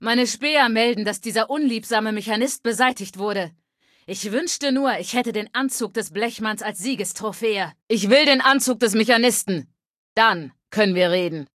Datei:Femaleadult01default ms02 greeting 00098cd7.ogg